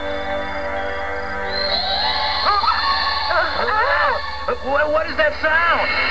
Pikachu Cries- This is a clip of all the Pikachu being captured during the episode, "Pikachu's Goodbye".
pikacry.wav